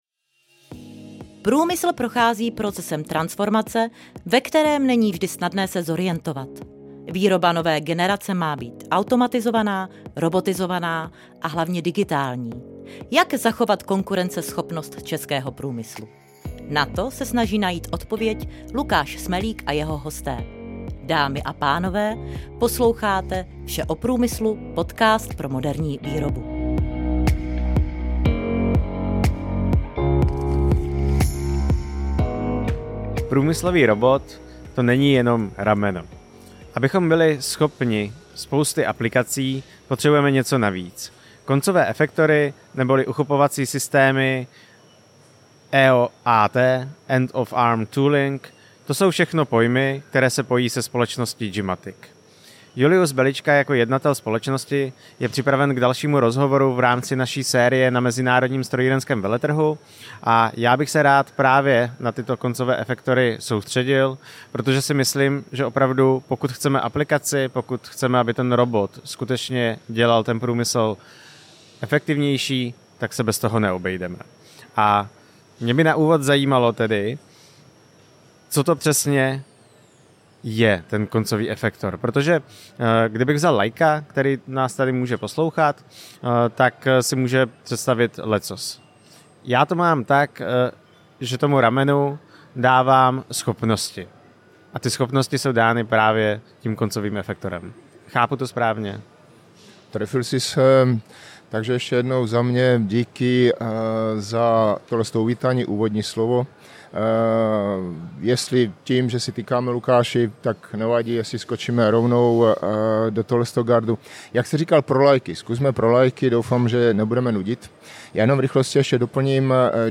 je dalším z respondentů série rozhovorů na MSV 2025 v Brně. Série je speciál ke konferenci ROBOTY, která každý rok mapuje trendy průmyslové robotizace.